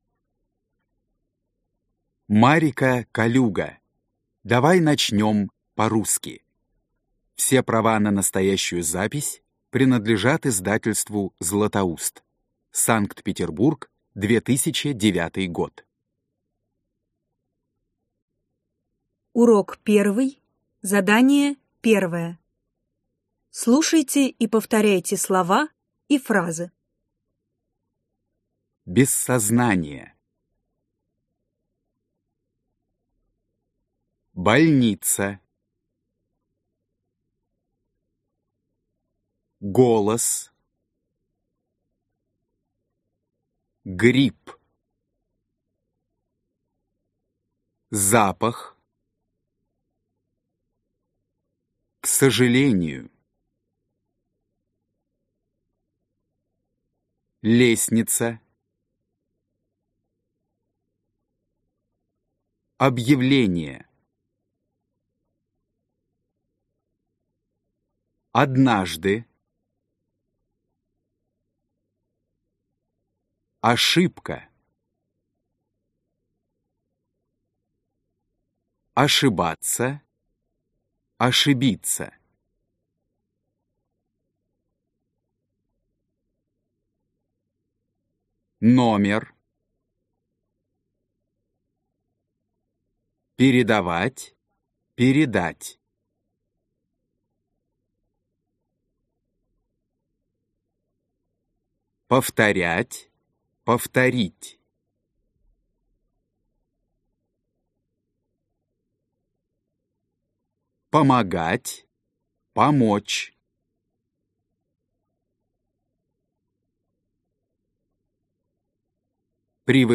Аудиокнига Давай начнем по-русски | Библиотека аудиокниг